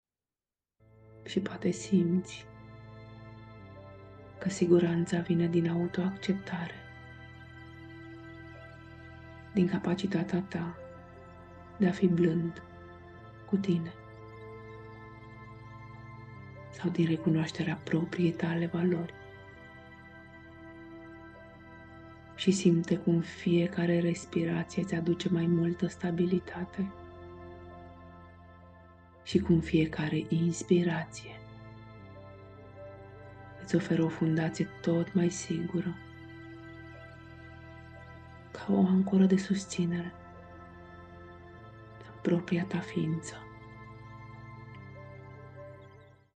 Meditatie audio Ghidata